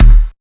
176UK2TOM1-L.wav